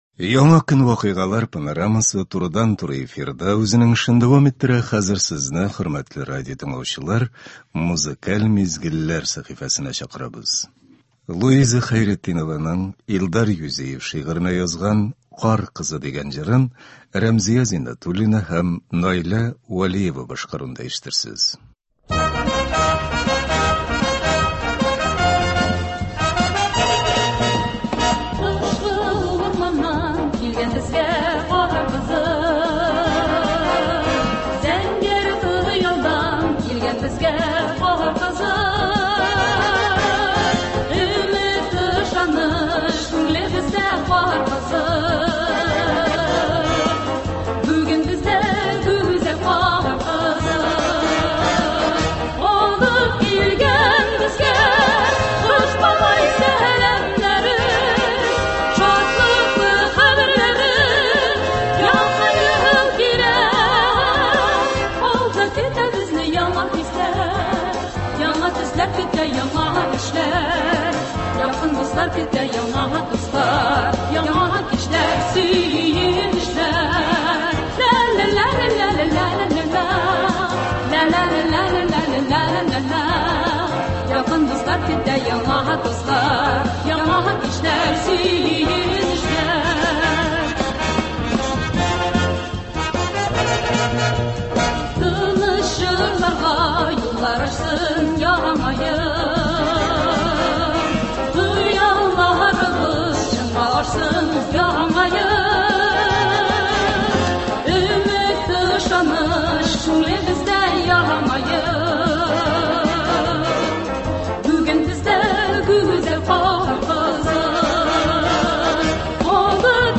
Музыкаль мизгелләр – һәр эш көнендә иртән безнең эфирда республикабыз композиторларының иң яхшы әсәрләре, халкыбызның яраткан җырлары яңгырый.